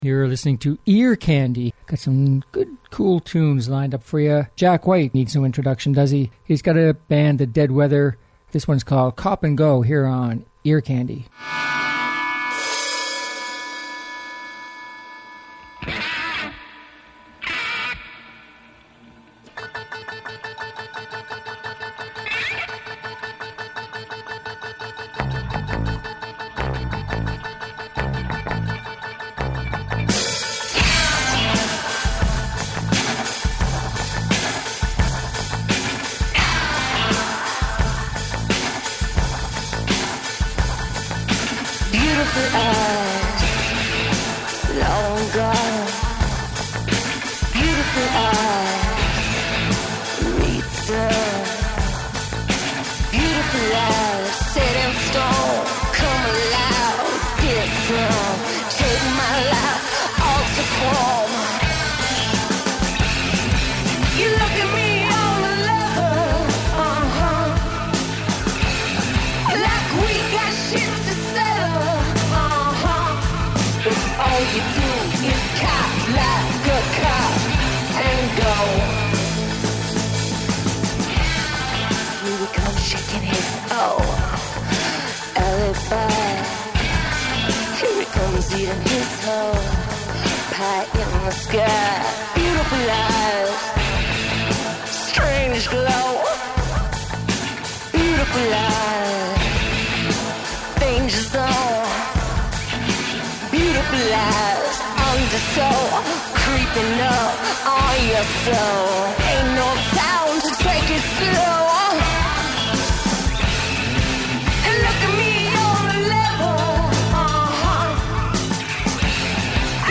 Catchy Uptempo Music From Old and New Artists